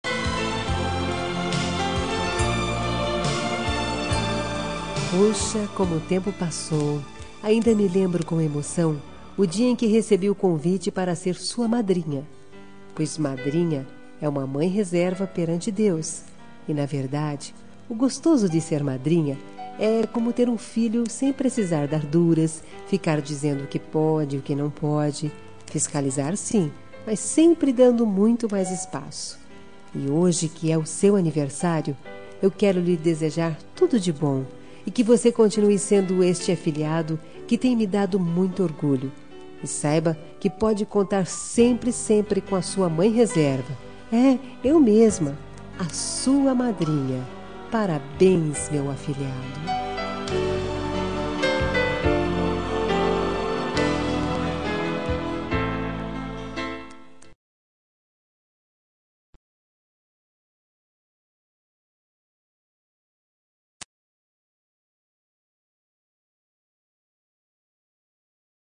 Aniversário de Afilhado – Voz Feminina – Cód: 2354